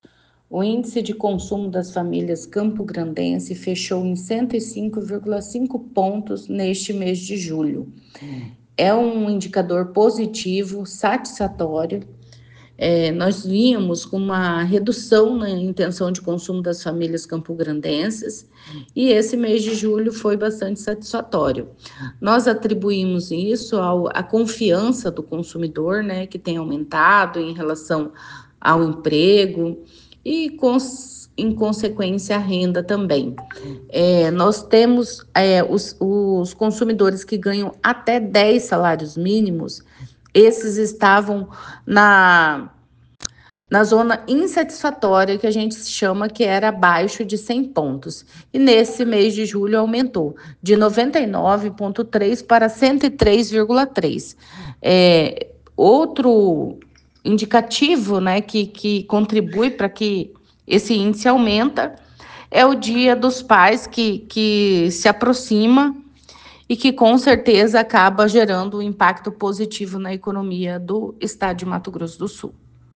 Em entrevista à FM Educativa de MS